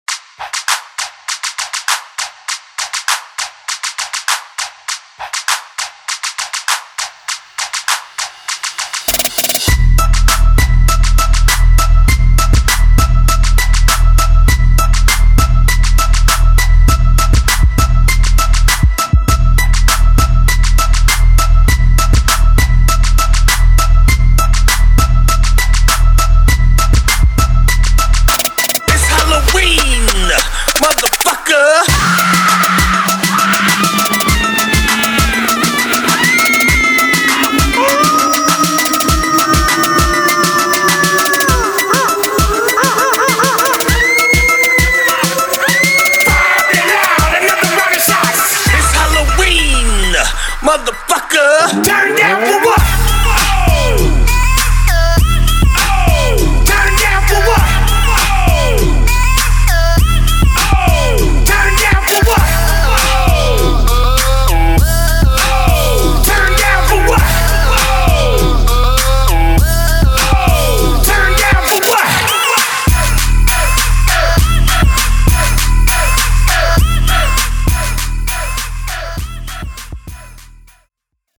Genre: HIPHOP
Clean & Dirty BPM: 98 Time